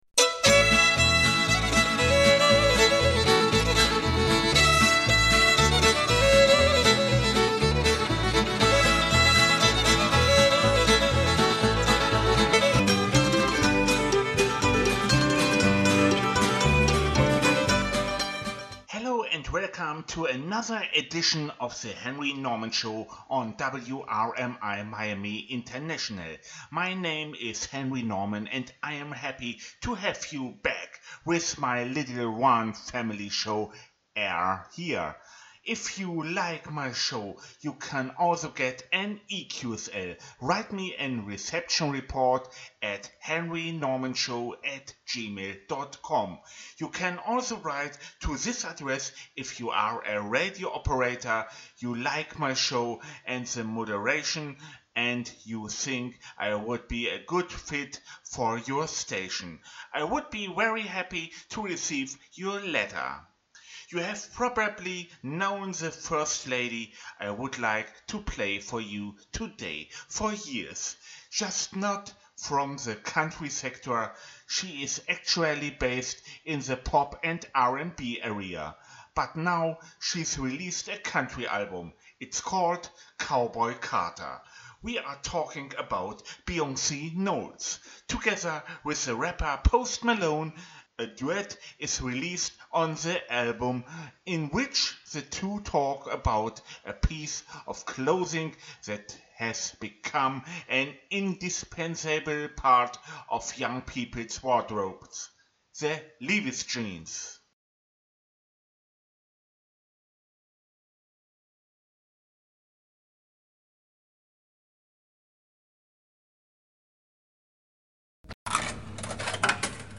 WRMI 9955 KHz - Country Music Edition